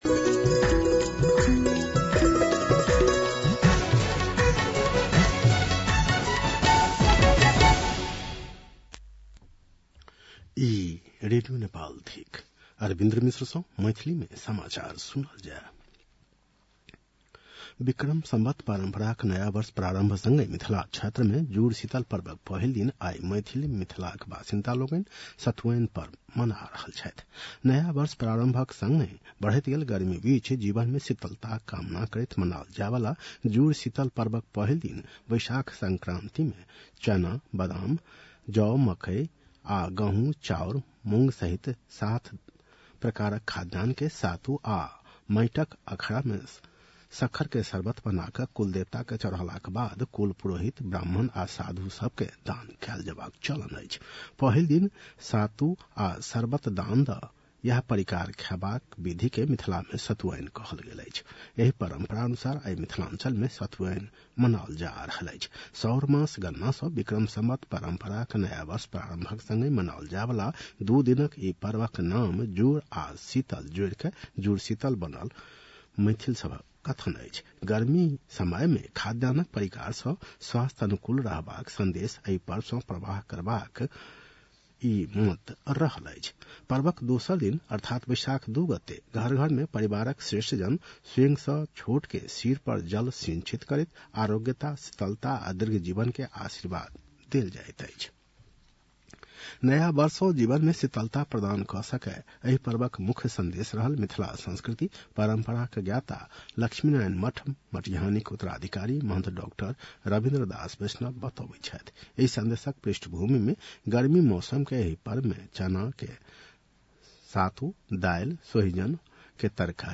मैथिली भाषामा समाचार : १ वैशाख , २०८२